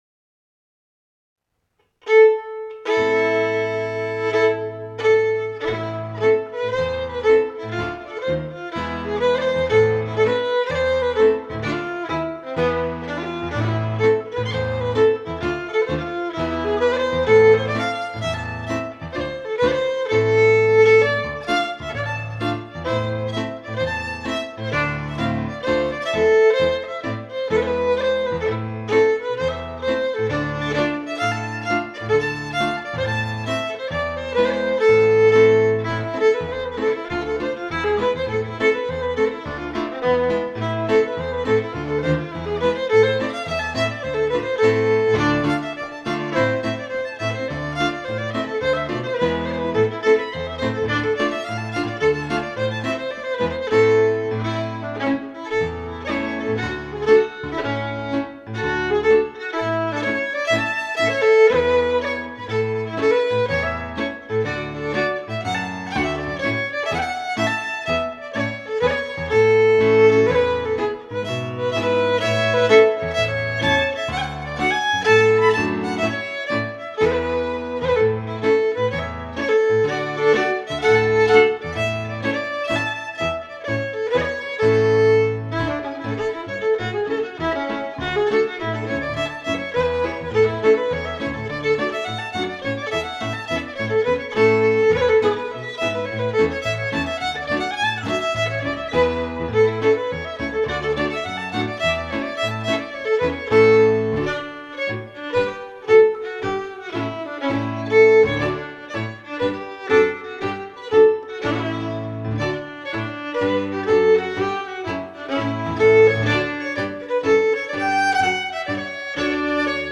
Traditional Scottish Fiddle Music
Strathspey / Reel